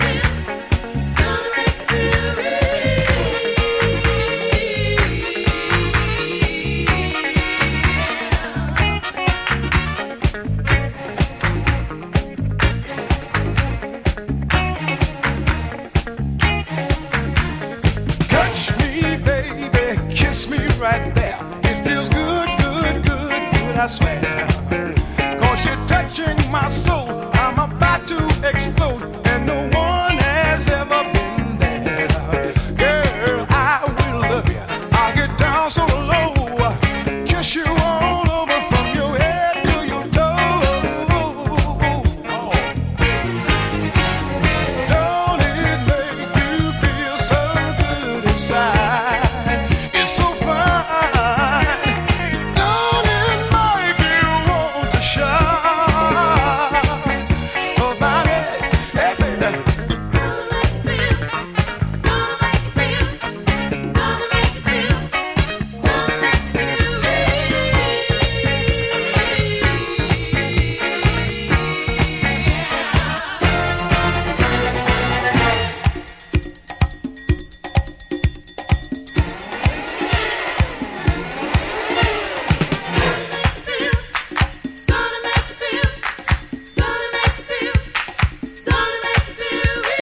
A killer deep-disco and very rare collectable single
Coming out in a 12inch version built for dancefloors